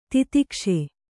♪ titikṣe